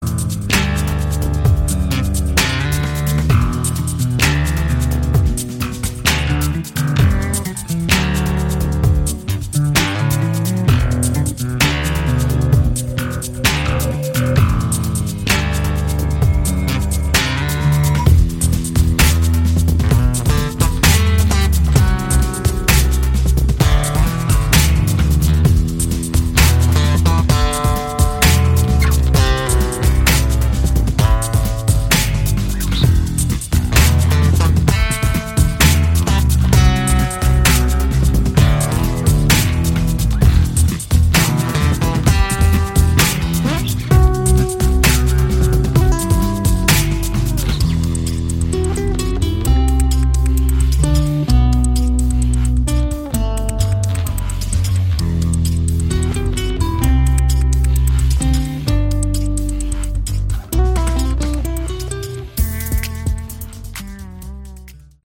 Category: Instrumental Hard Rock